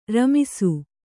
♪ ramisu